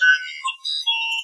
Computer2.wav